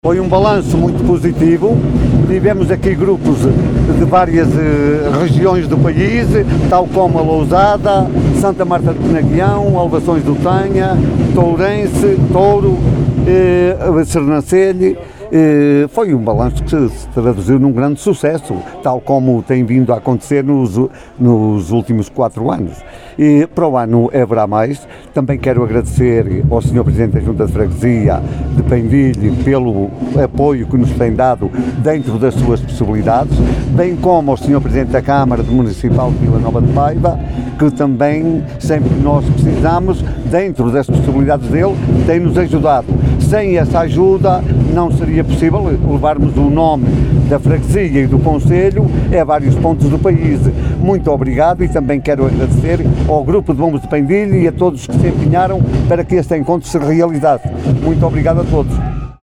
em declarações à Alive FM, fez um balanço muito positivo deste 5º Encontro de Bombos.